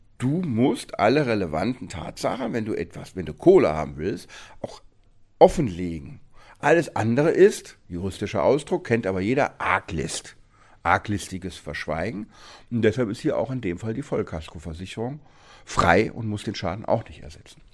O-Ton: Kasko zahlt nach angeblichem Wildunfall mit verschwiegenen Altschäden nicht – Vorabs Medienproduktion